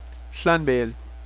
(click to hear pronunciation).